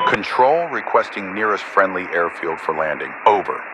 Radio-playerATCRequireNearestAirbase2.ogg